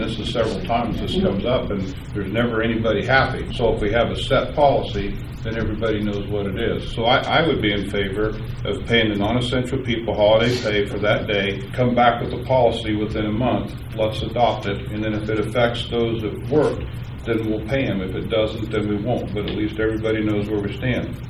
Commissioner Greg Riat says that he would like a policy to be established so county employees will know how they will be paid in these kinds of circumstances going forward.